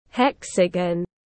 Hình lục giác tiếng anh gọi là hexagon, phiên âm tiếng anh đọc là /’heksægən/.
Hexagon /’heksægən/